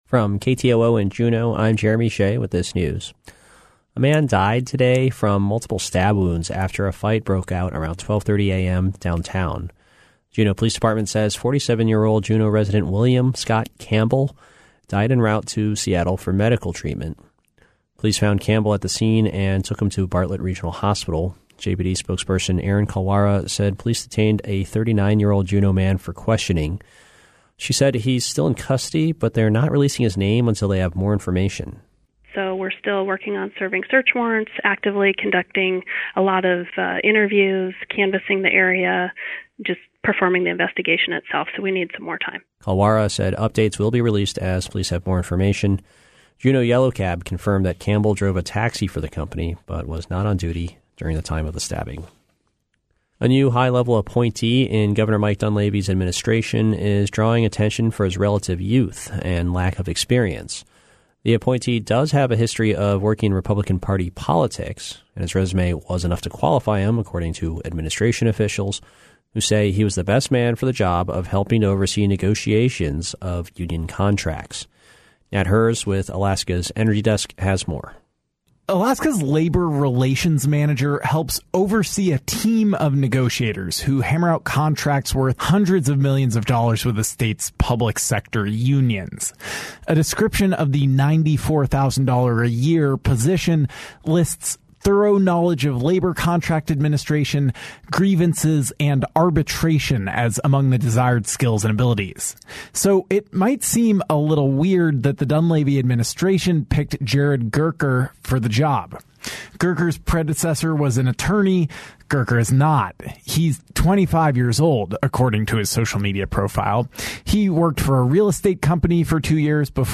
Newscast – Thursday, May 2, 2019